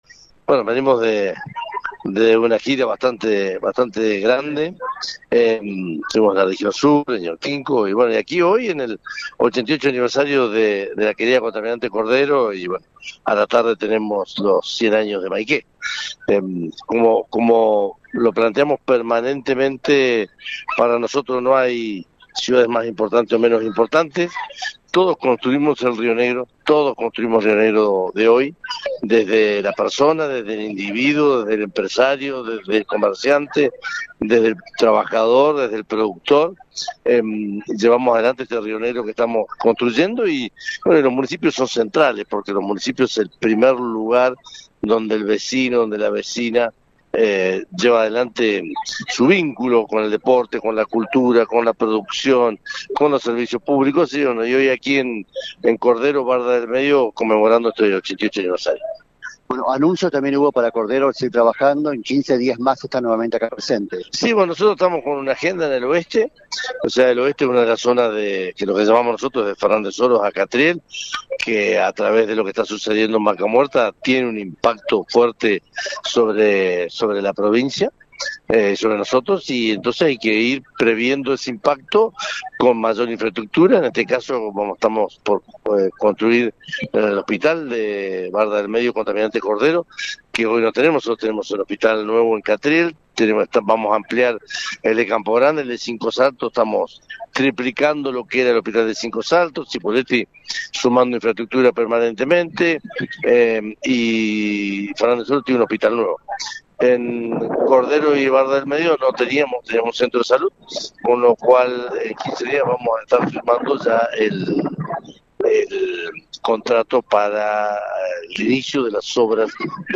El gobernador Alberto Weretilneck acompañó al intendente Horacio Zuñiga y a la comunidad en el 88° aniversario de Contralmirante Cordero y Barda del Medio, donde anunció que en quince días se firmará el contrato para iniciar la construcción del nuevo edificio del hospital, financiado con el bono petrolero del acuerdo con las empresas del oleoducto Vaca Muerta Oil Sur.